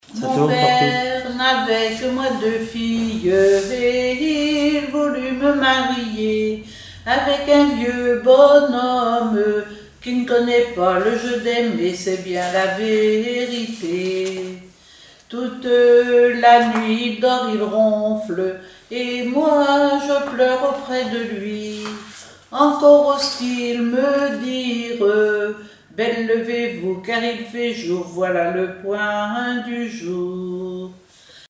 Genre strophique
Chansons et formulettes enfantines
Pièce musicale inédite